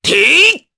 Oddy-Vox_Attack3_jp.wav